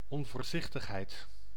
Ääntäminen
Synonyymit inconscience Ääntäminen France: IPA: [ɛ̃.pʁy.dɑ̃s] Haettu sana löytyi näillä lähdekielillä: ranska Käännös Ääninäyte Substantiivit 1. onvoorzichtigheid 2. verdwaasdheid 3. zinneloosheid Suku: f .